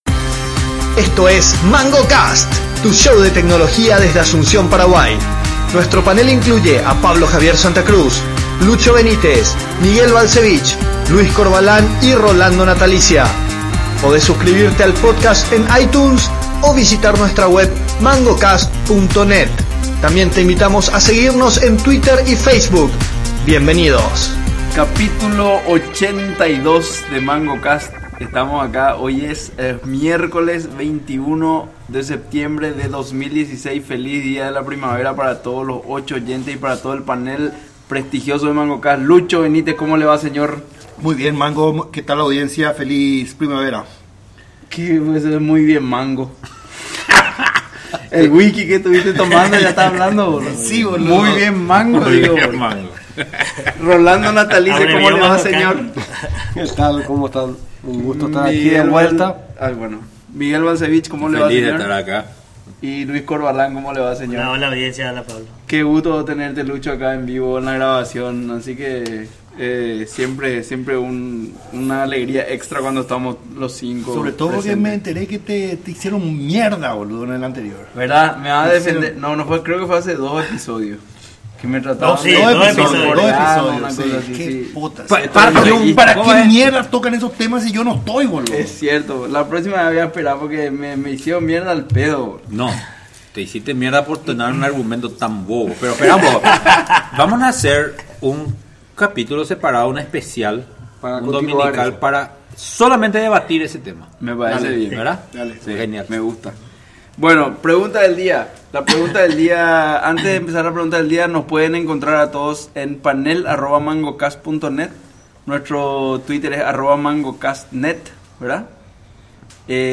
Pero lo importante es que el panel estuvo completo, la noche estuvo muy linda, grabamos afuera, y comimos un asado para chuparse los dedos.